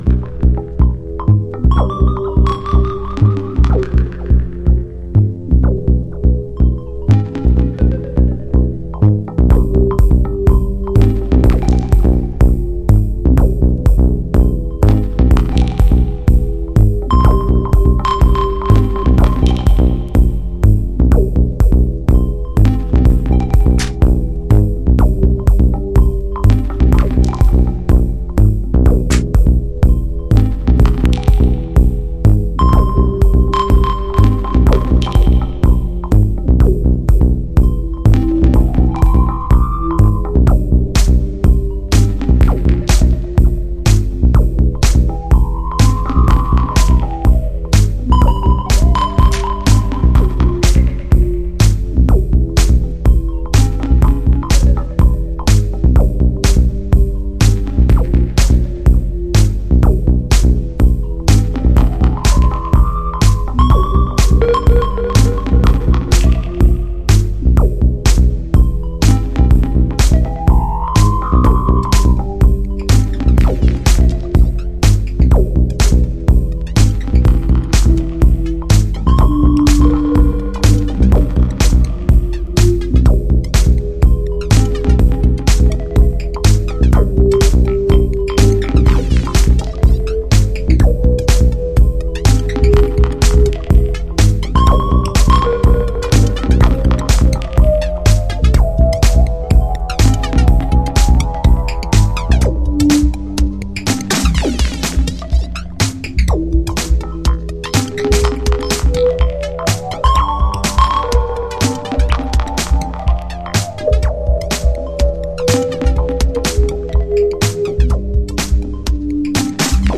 House / Techno
基本的なエフェクトを多用してグルーヴキープ、砕け散る粒子が心地良いディープテクノ。